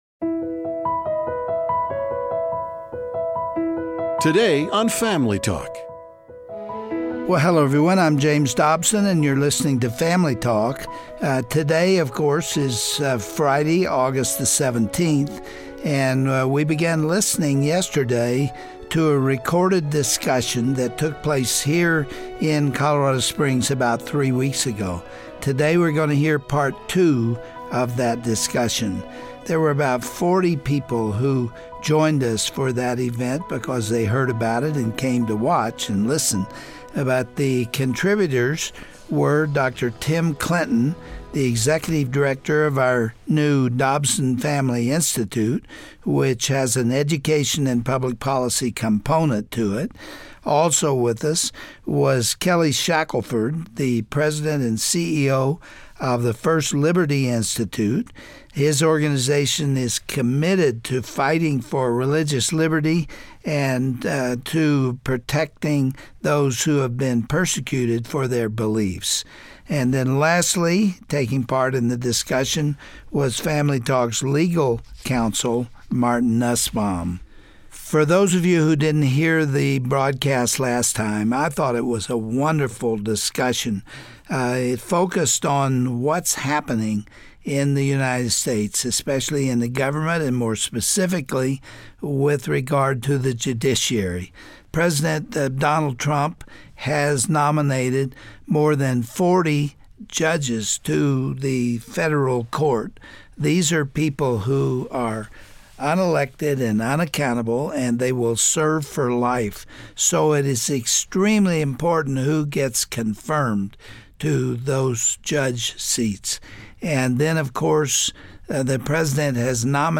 youll hear the conclusion to an in-depth panel discussion Dr. Dobson was a part of last month on the need for the Christian voice to be heard in politics. The conversation centered around how the appointment of Judge Brett Kavanaugh could impact the Supreme Court and why an originalist on the bench will steer the court back to its primary purpose.